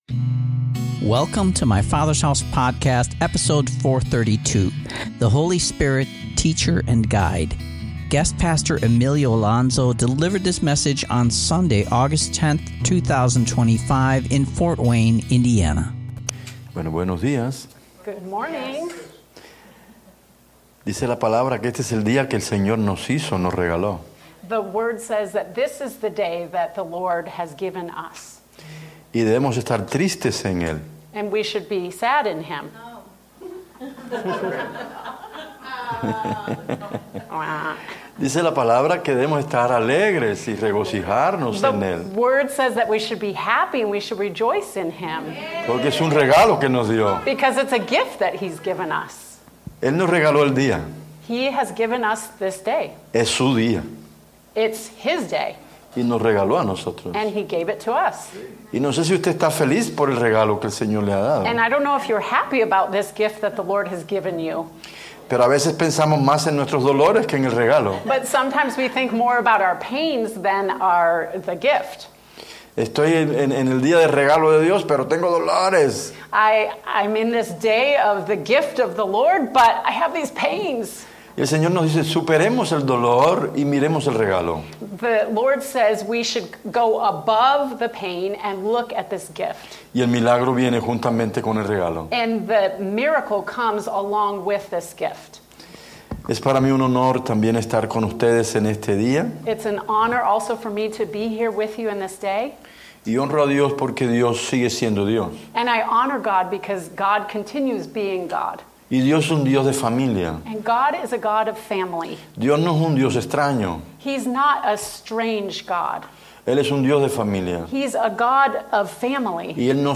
Guest Minister